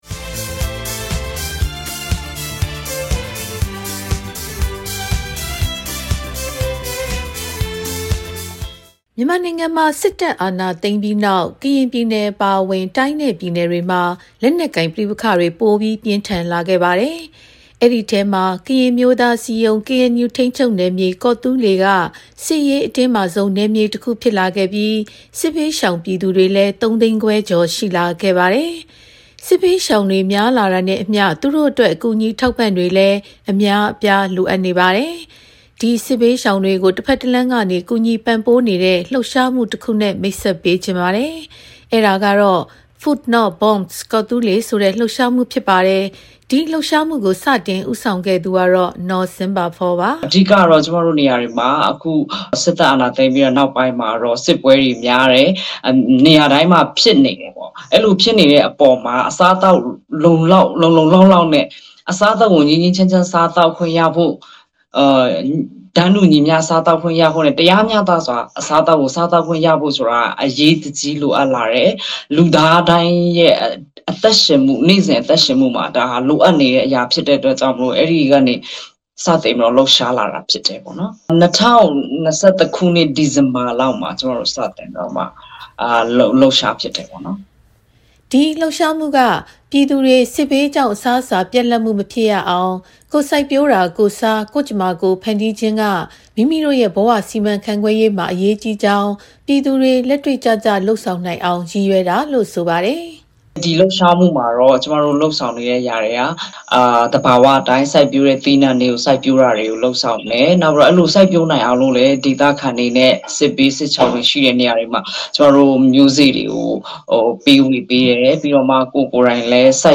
မေးမြန်းတင်ပြထားပါတယ်။